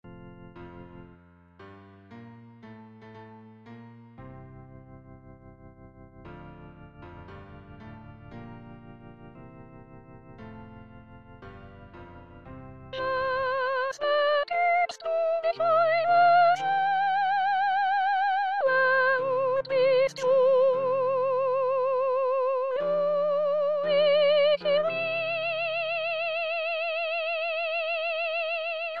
Introduction 5 voix, mes.  1-13